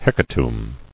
[hec·a·tomb]